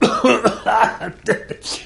00002_Sound_husten.mp3